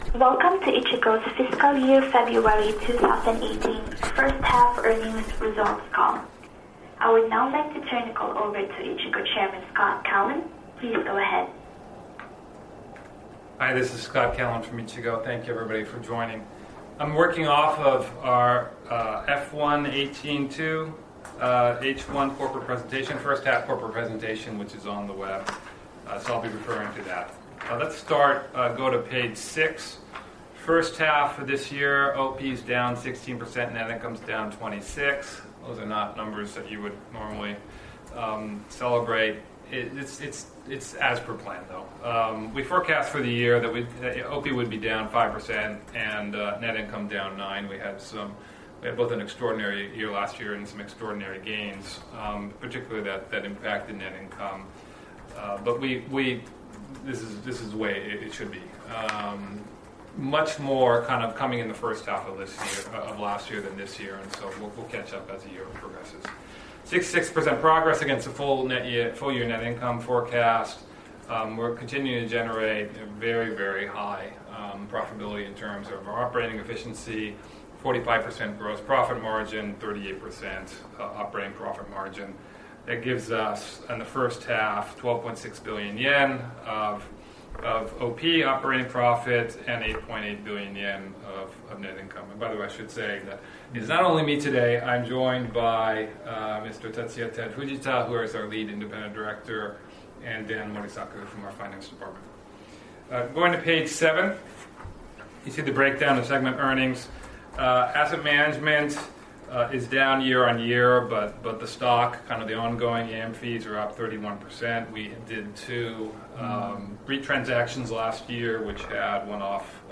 Earnings Call